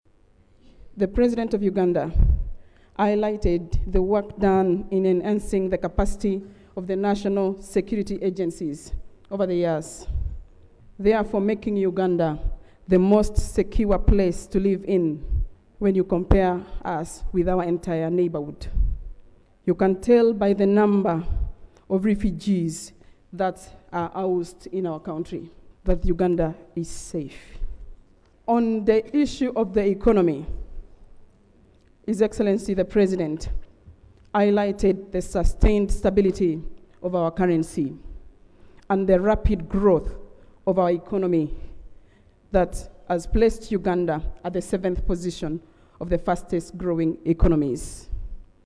Hon. Faith Nakut moves the motion appreciating the President for the clear and precise exposition of government policy as contained in the state of the nation address